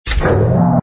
engine_ku_freighter_start.wav